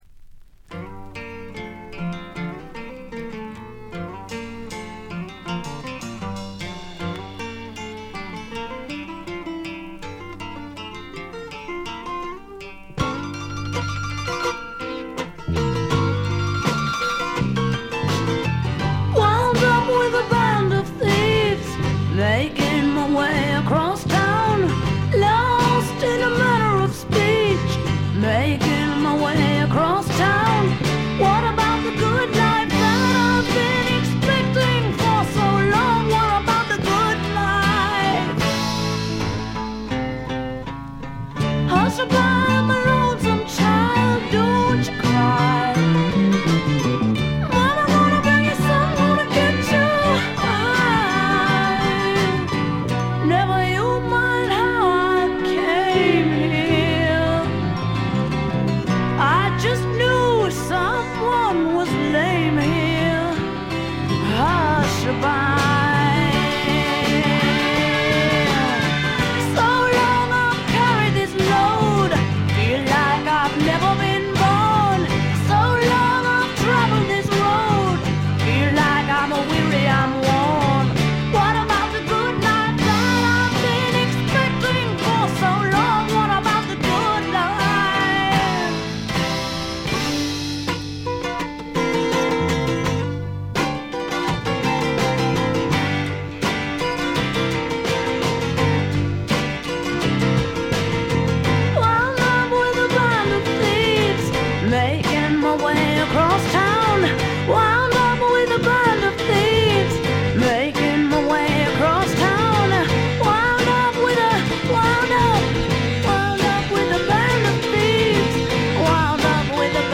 わずかなノイズ感のみ。
カナダ出身の女性シンガーソングライターが残したサイケ／アシッド・フォークの大傑作です。
試聴曲は現品からの取り込み音源です。